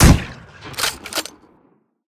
glShoot.ogg